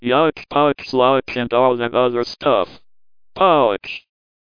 DECtalk allows you to adjust two undocumented parameters, f7 and f8. While the results of f8 aren't entirely clear, f7 definitely does something.